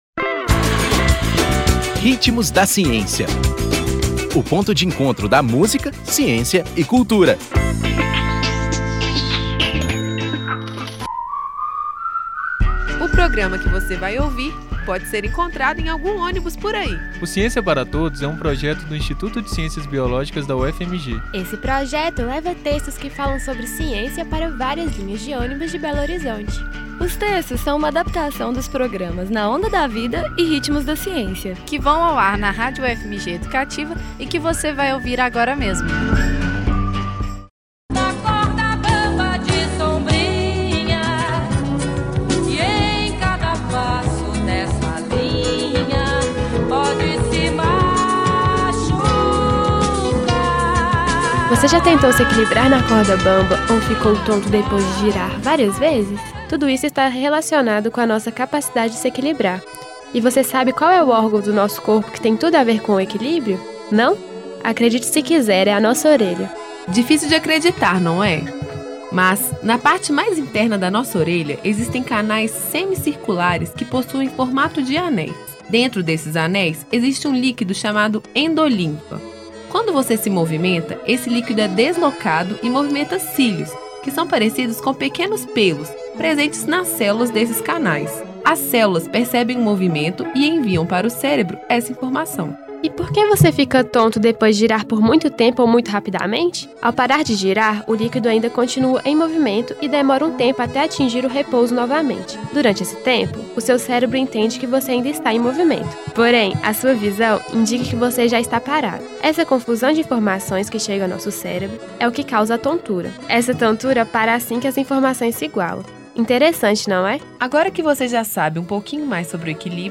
Nome da música: O Bêbado e a Equilibrista
Intérprete: Elis Regina